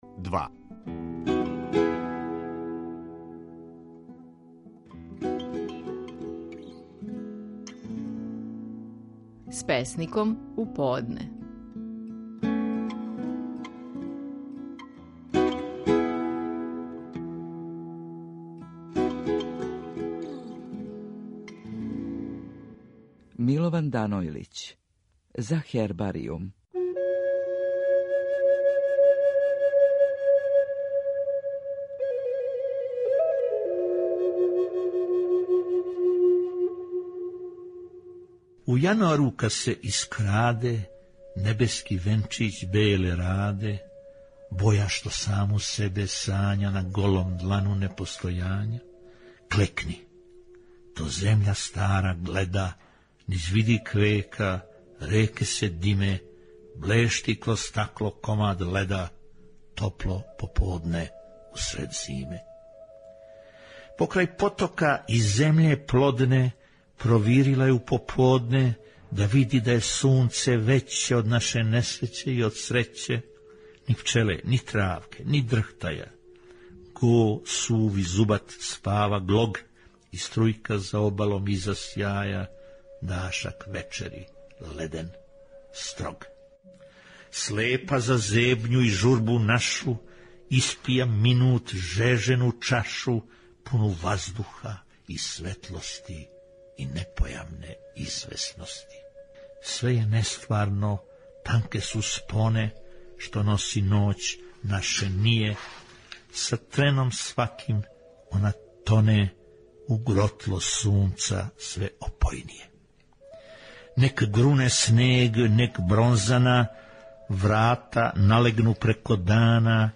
Стихови наших најпознатијих песника, у интерпретацији аутора.
Слушамо Милована Данојлића и његову песму „За хербаријум".